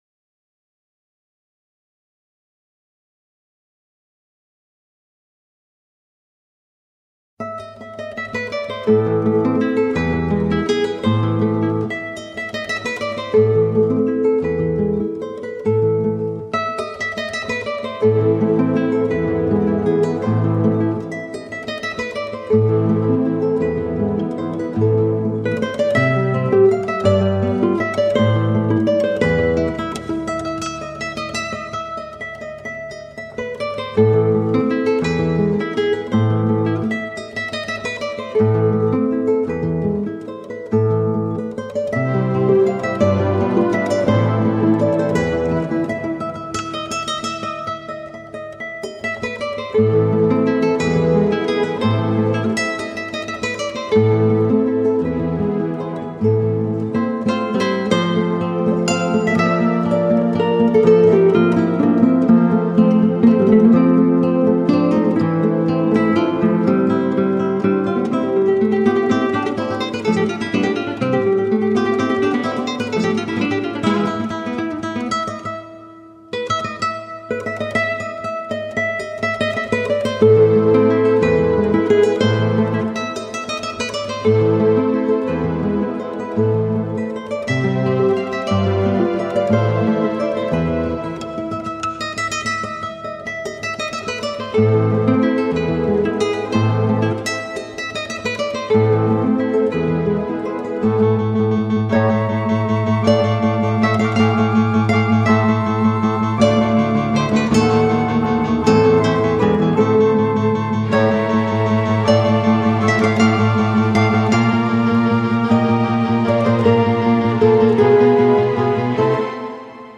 0288-吉他名曲致爱丽丝.mp3